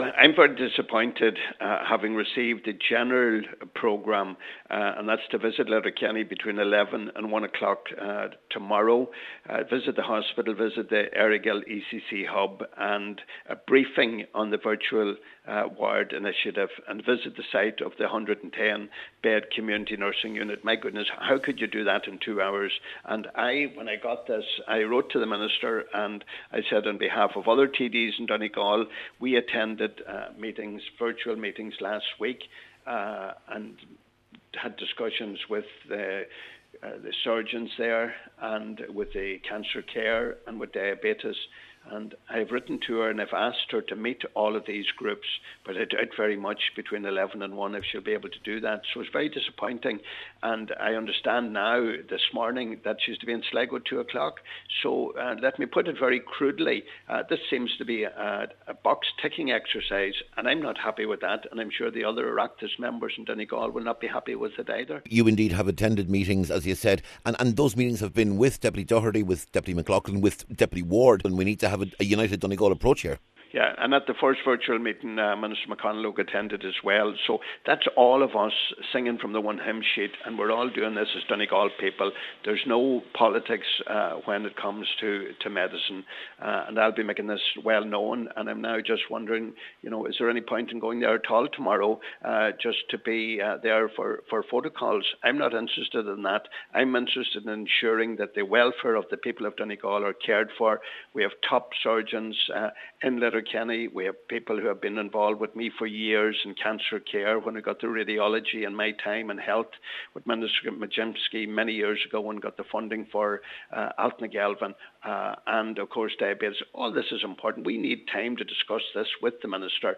Deputy Gallagher says he’s written to the minister seeking a number of meetings, but as of yet, he has not received a response…………